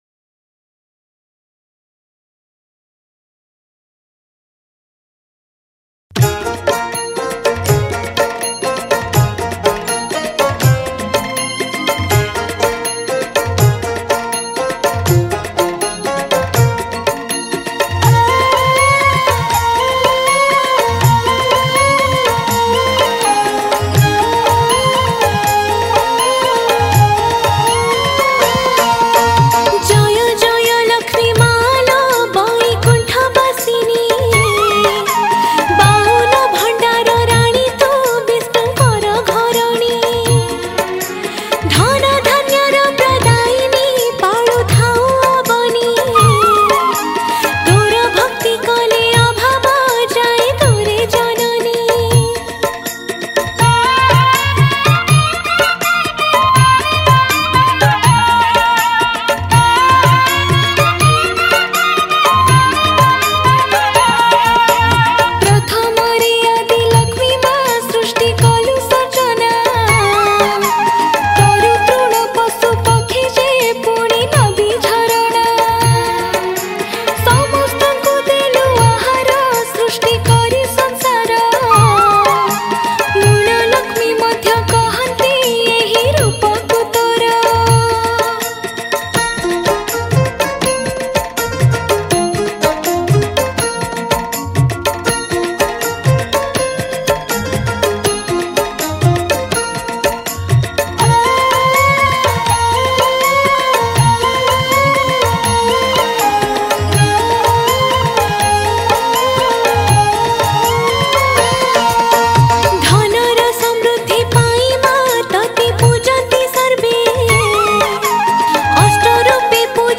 New Odia Bhajan 2023